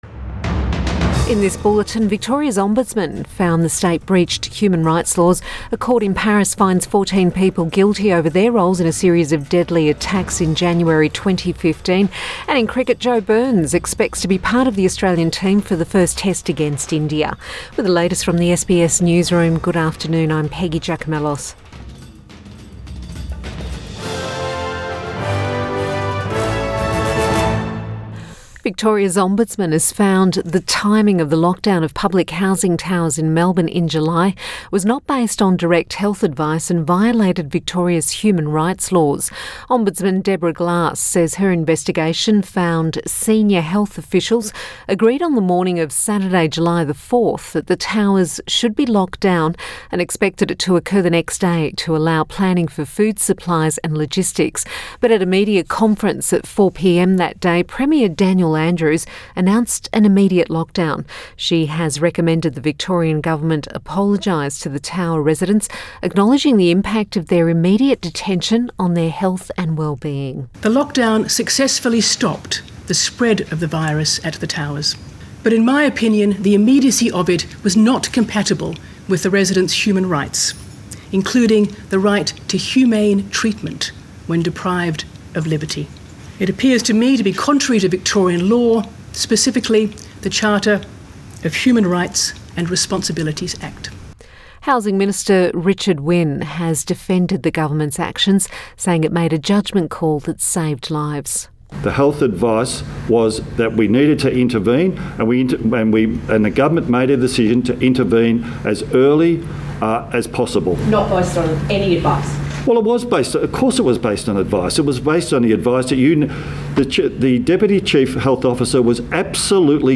Midday bulletin 17 December 2020